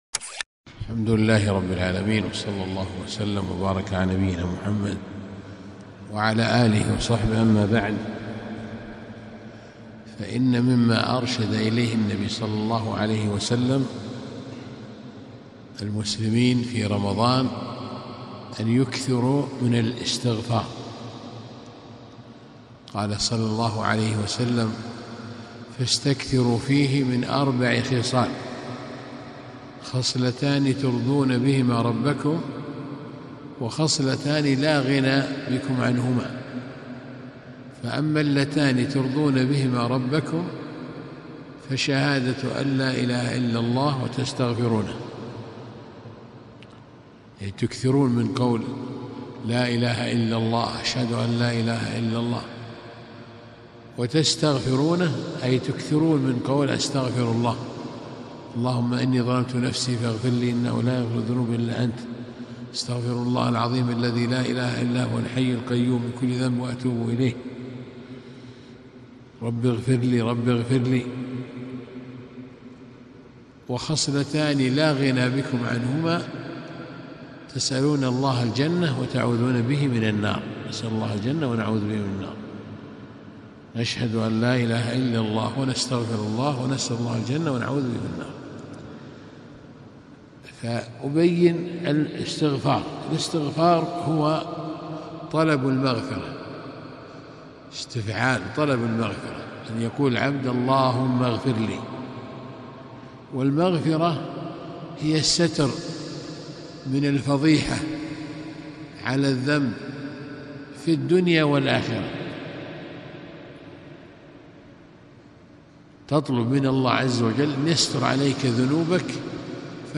محاضرة قيمة - الاستغفار وفضائله